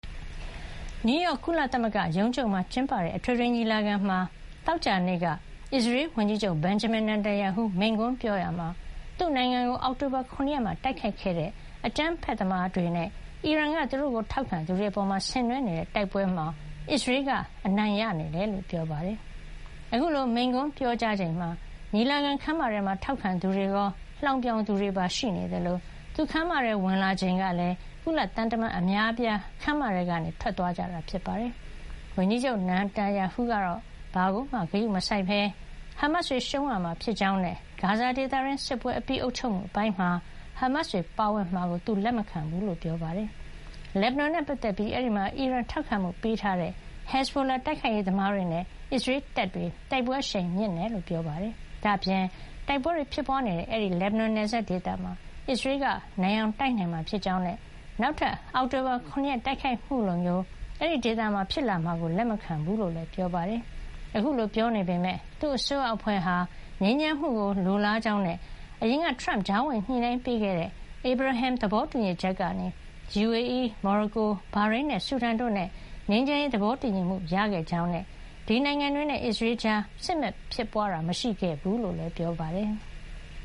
နယူးရောက် ကုလသမဂ္ဂရုံးချုပ်မှာကျင်းပတဲ့ အထွေထွေညီလာခံမှာ သောကြာနေ့က အစ္စရေးဝန်ကြီးချုပ် Benjamin Natanyahu မိန့်ခွန်းပြောရာမှာ၊ သူ့နိုင်ငံကို အောက်တိုဘာ ၇ ရက်မှာ တိုက်ခိုက်ခဲ့တဲ့ အကြမ်းဖက်သမားတွေနဲ့ အီရန်က သူ့တို့ကို ထောက်ခံသူတွေပေါ် ဆင်နွဲနေတဲ့ တိုက်ပွဲမှာ အစ္စရေးက အနိုင်ရနေကြောင်း ပြောပါတယ်။